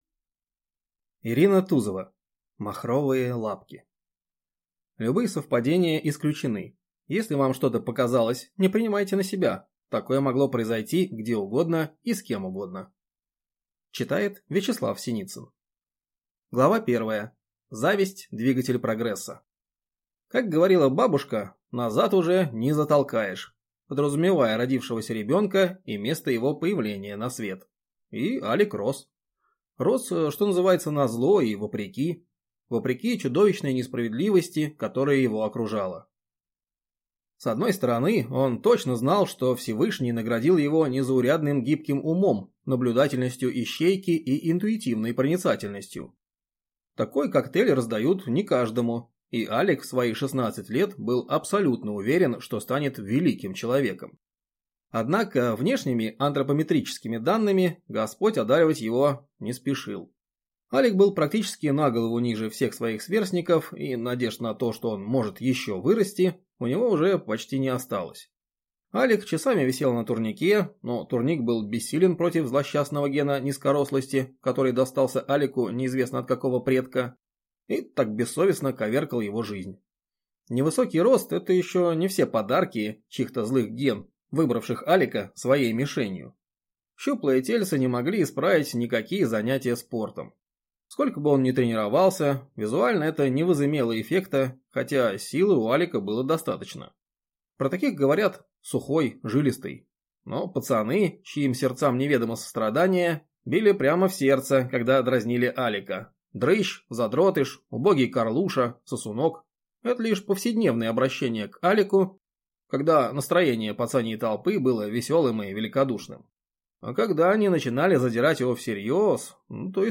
Аудиокнига Махровые лапки | Библиотека аудиокниг